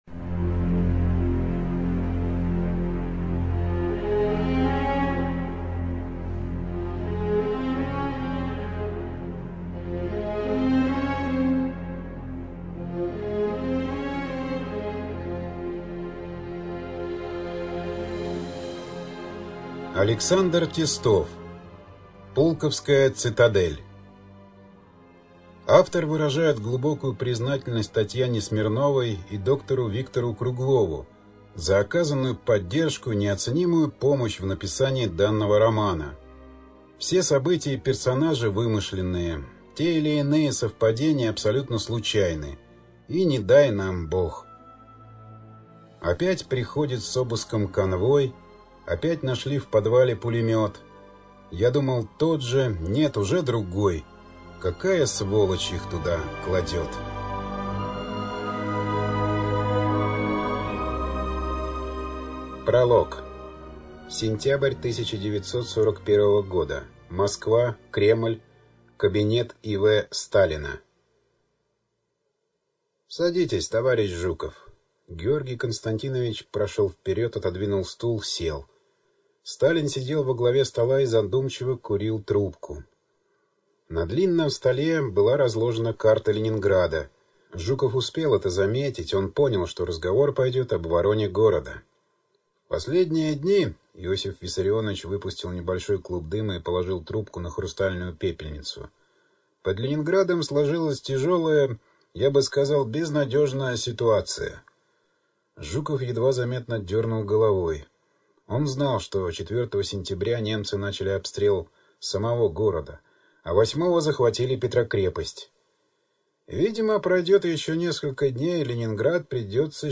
ЖанрФантастика, Ужасы и мистика